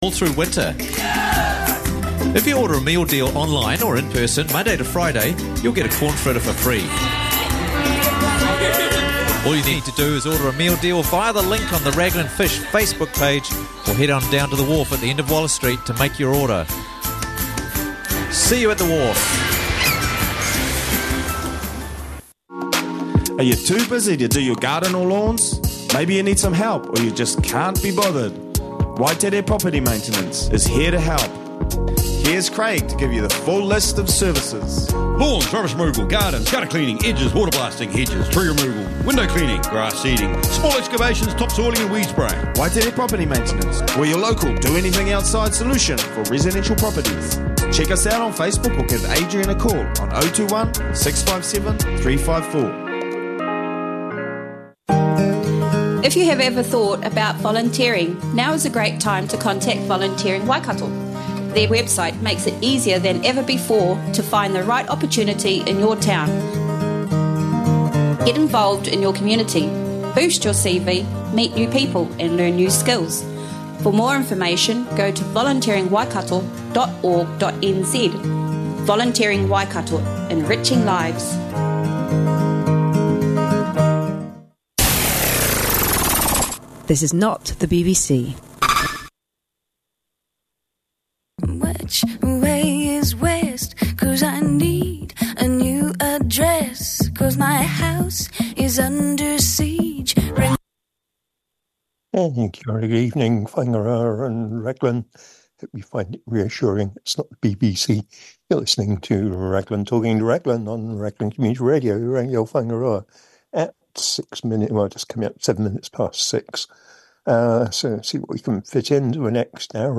An update on local news, plus a couple of interesting interviews from the Morning Show